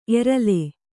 ♪ erale